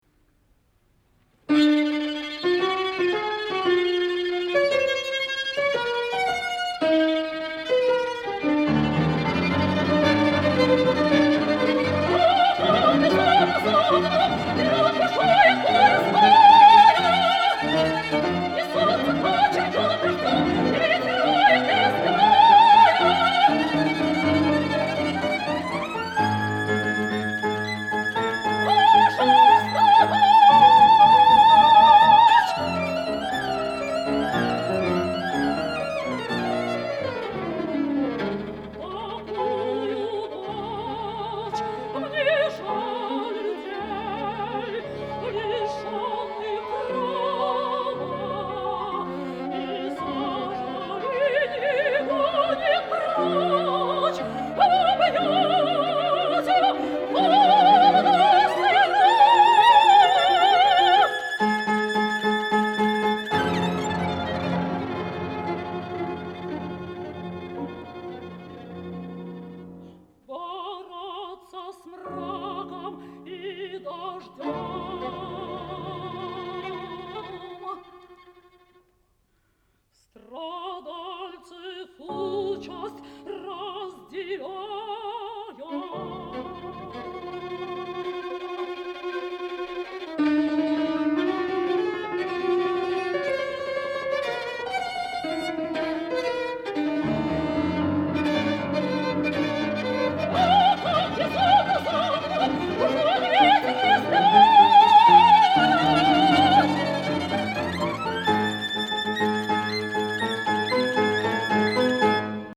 Прослушивание аудиозаписи романса